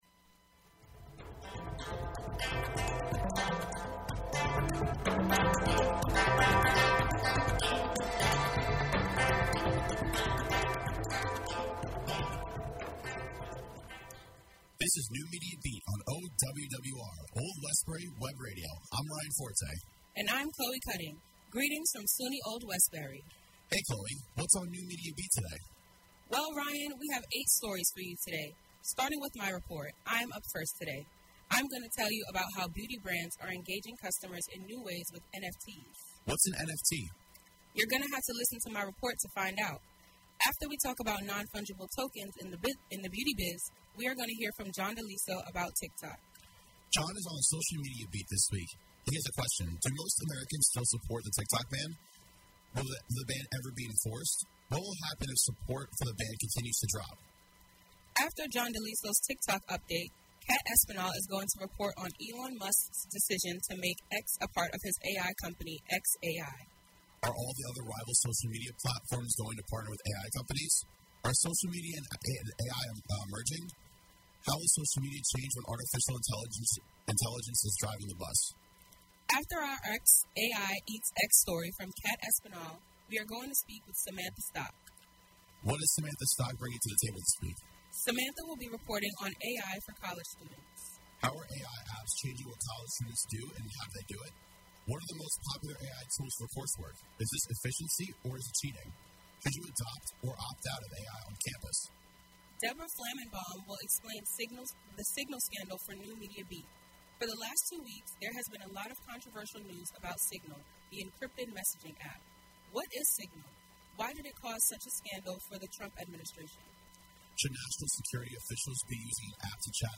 The NMB Podcast streams live on Old Westbury Web Radio Thursdays from 12:00-1:00 PM EST. Can’t listen live?